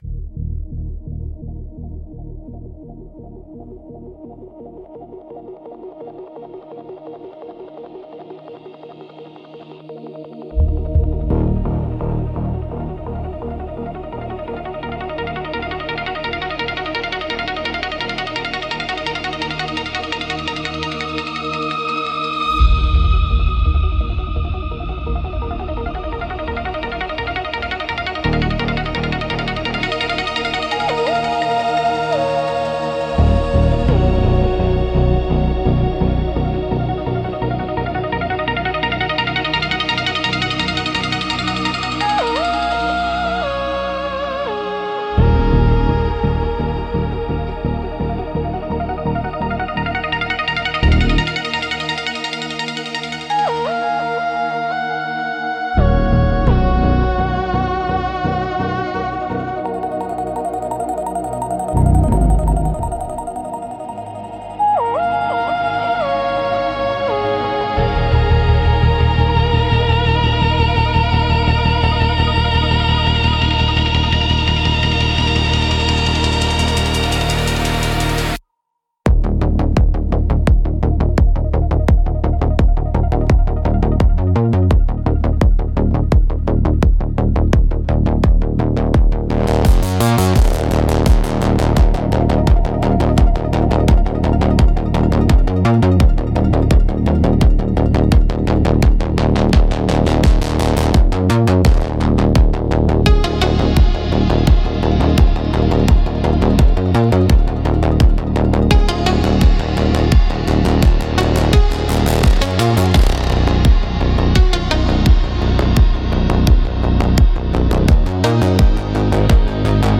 Instrumentals - The Ashes of a Silent Bell